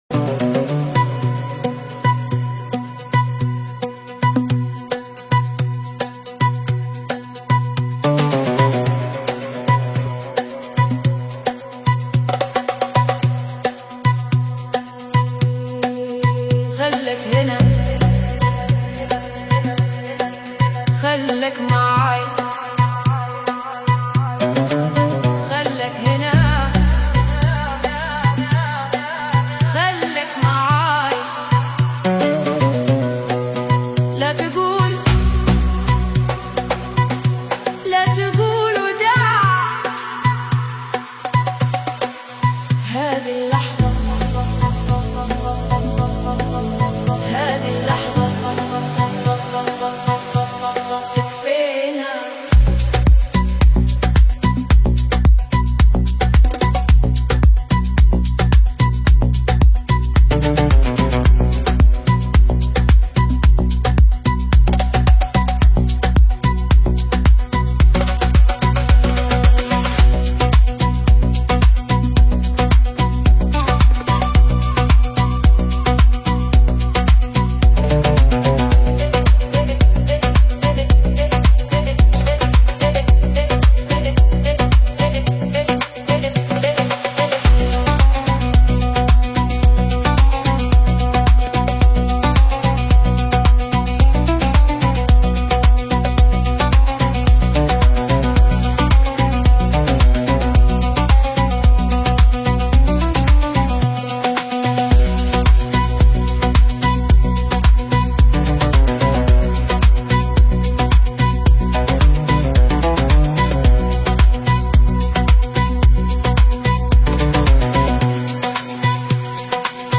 Organic House Ethnic Deep House